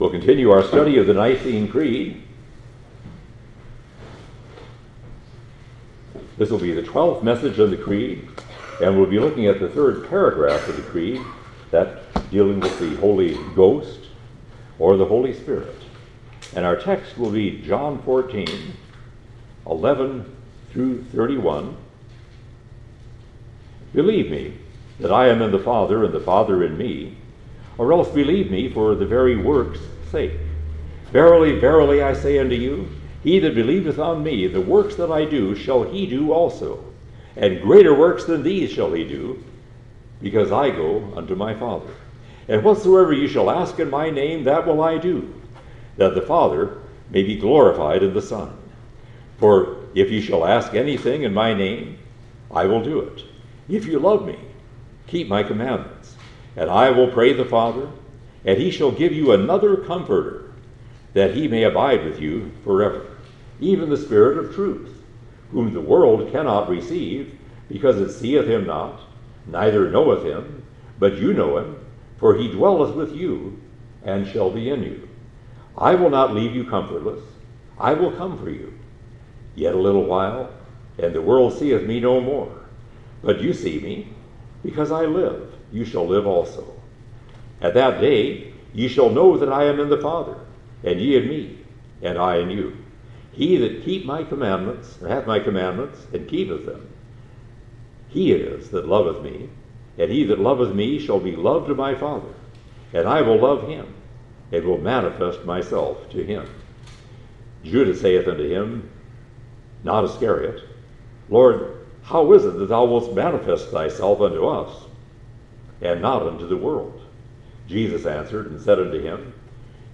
2025 Preacher